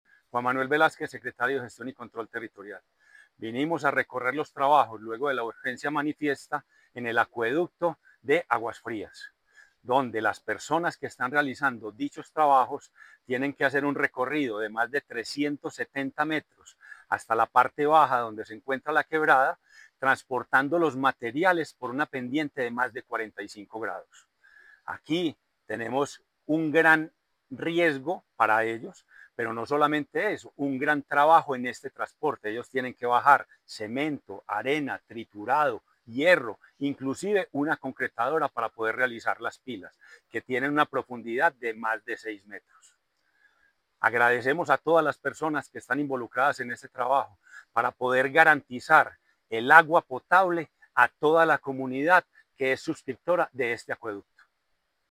Declaraciones secretario de Gestión y Control Territorial, Juan Manuel Velásquez Correa
Declaraciones-secretario-de-Gestion-y-Control-Territorial-Juan-Manuel-Velasquez-Correa.mp3